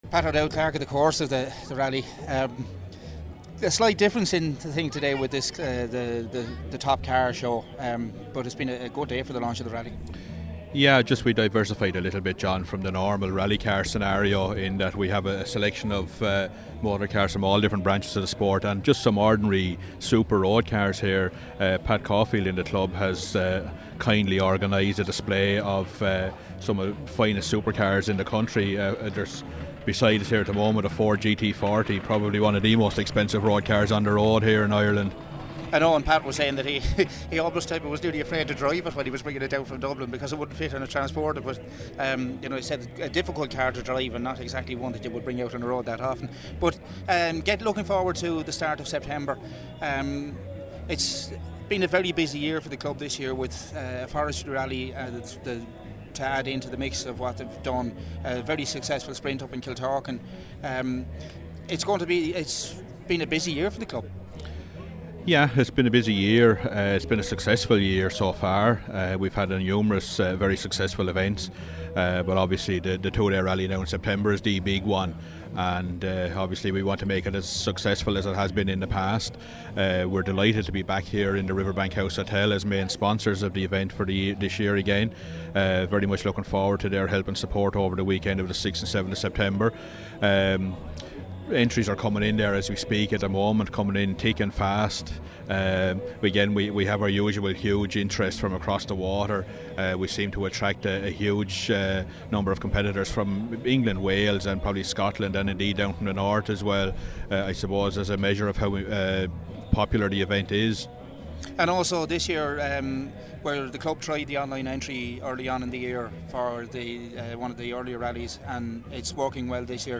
Not content with giving you pictures, we also did a few interviews at the launch of the Riverbank House Hotel Dick Bailey Stages Rally.